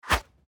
monster_atk_arrow_4.mp3